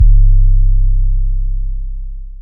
YM 808 13.wav